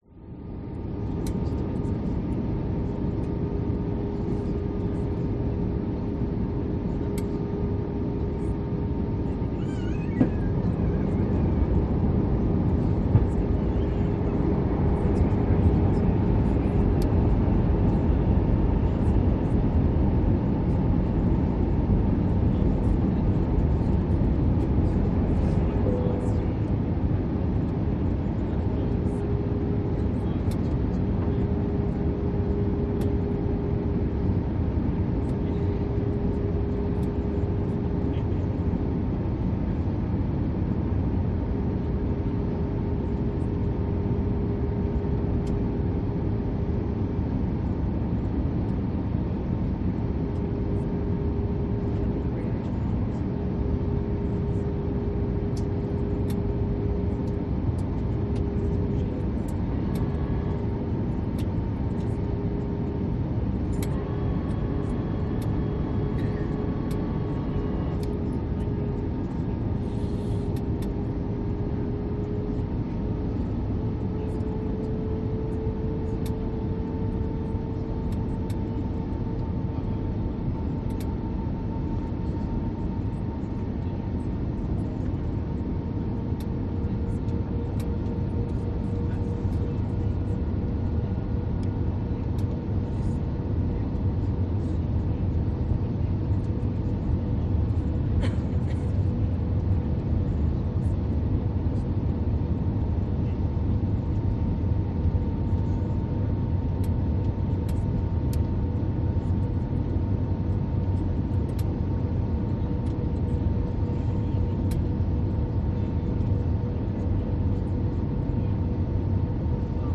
Реалистичные записи передают гул двигателей, скрип тормозов и другие детали, создавая эффект присутствия на взлетно-посадочной полосе.
Звуки в салоне самолета после посадки при движении к аэропорту